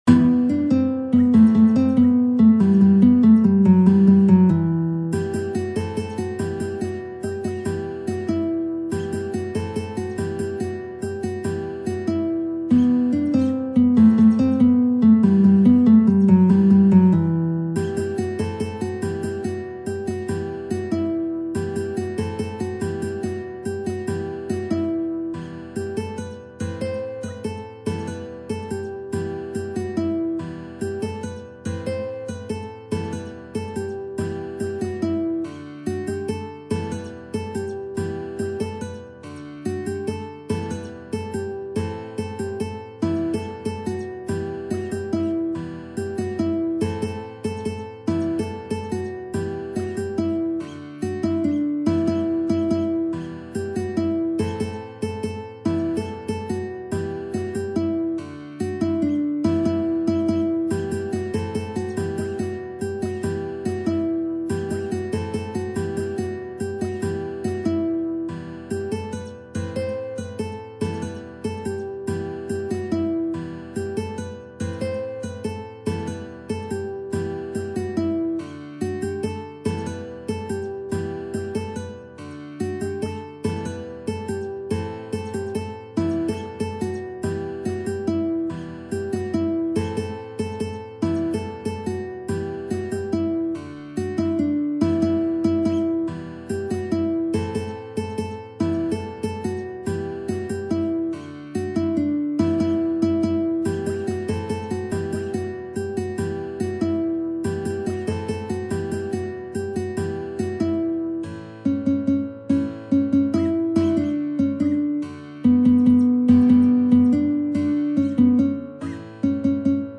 نت ملودی به همراه تبلچر و آکورد